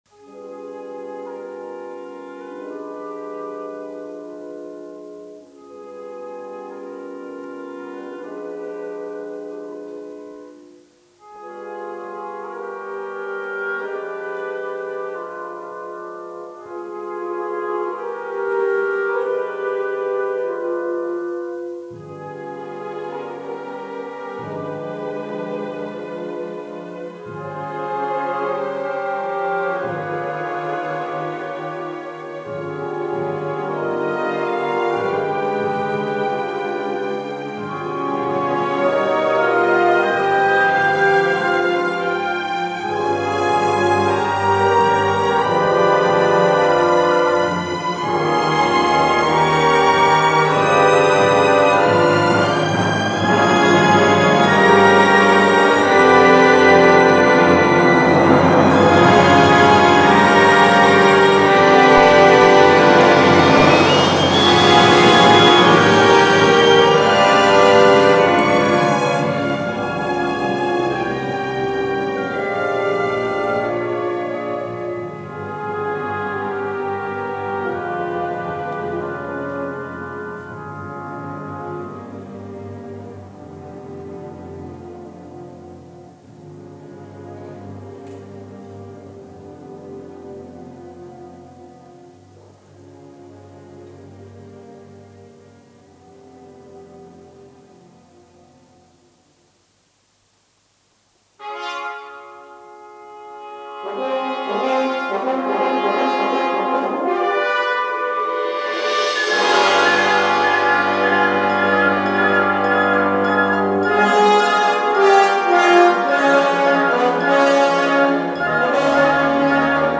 violino
Musica Classica / Sinfonica